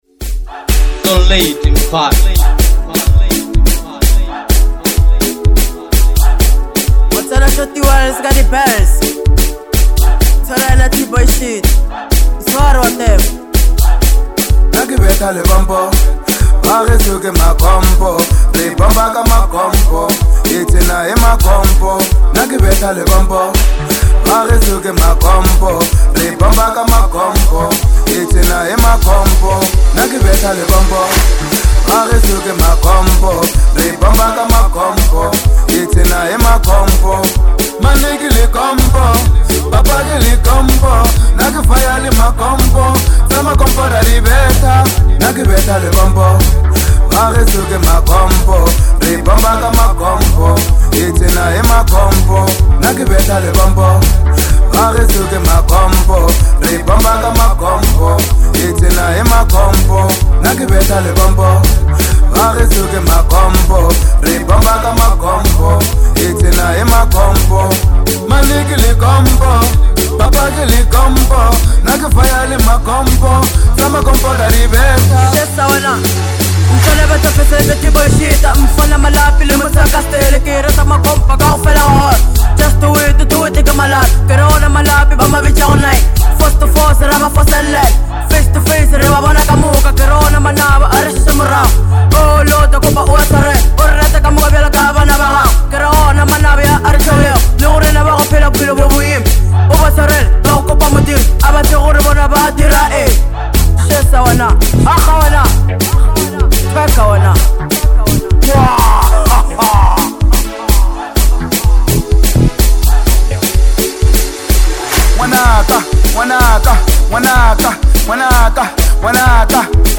Genre : Bolo House